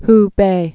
(hbā) also Hu·peh (-pā)